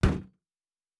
Metal Box Impact 2_3.wav